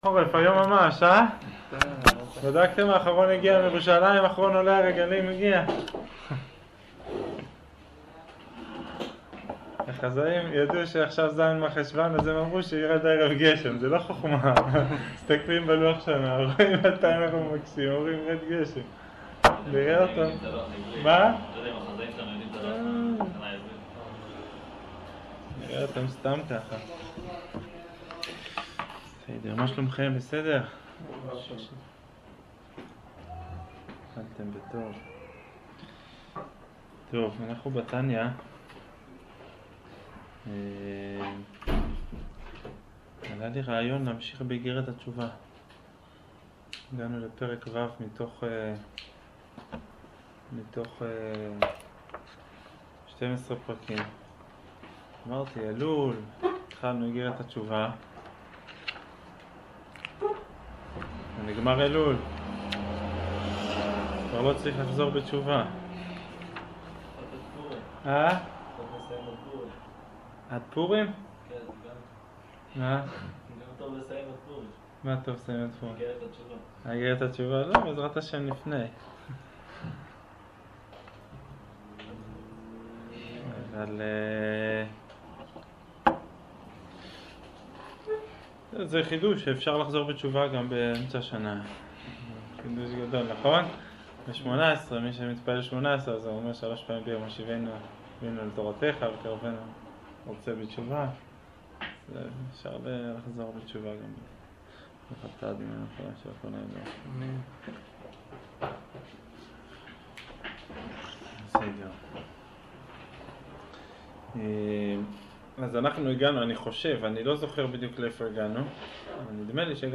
שיעור אגרת התשובה